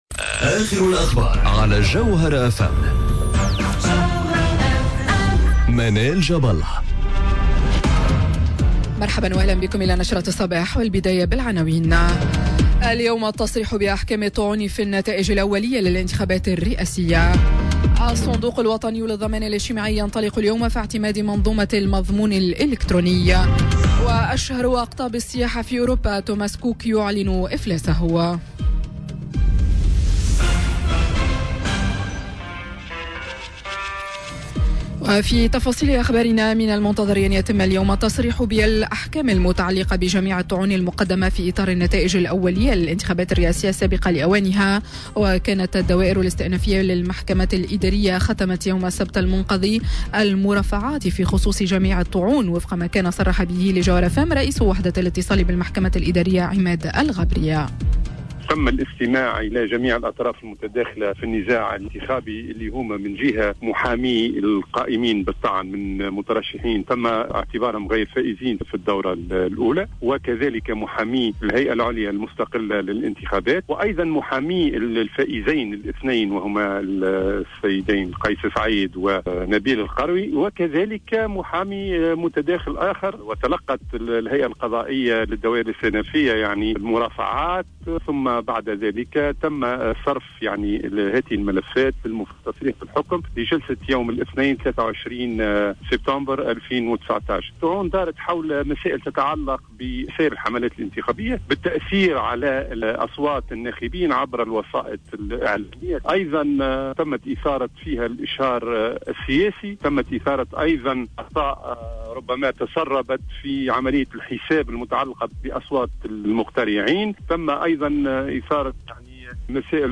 نشرة أخبار السابعة صباحا ليوم الإثنين 23 سبتمبر 2019